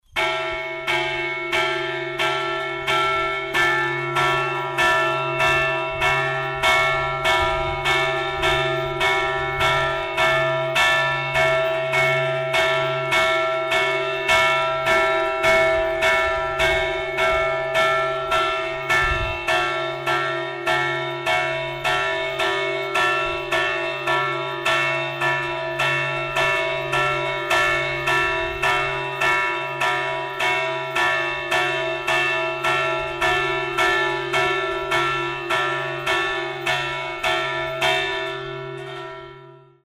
Toques de campanas en formato MP3
Alarma 0:39 alarma badajazos